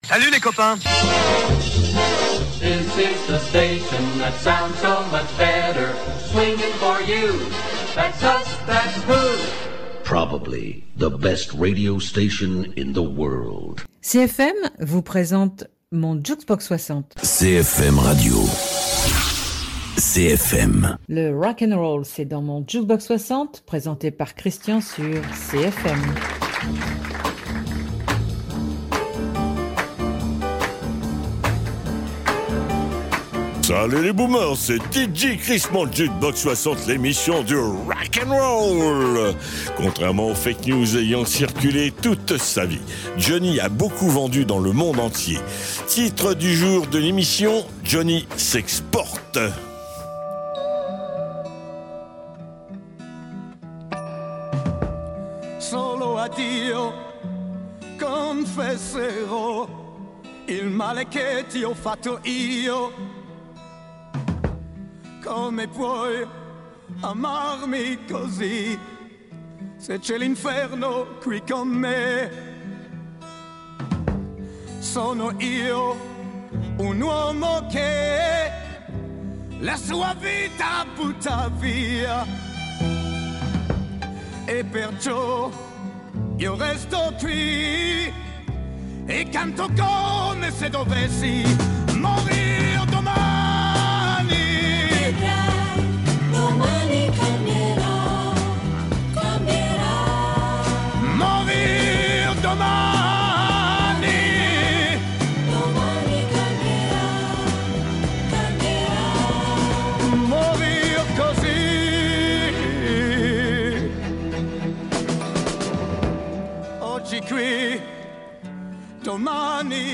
Invité(s) : johnny chante en duo à la télévision espagnole avec Julio Iglesias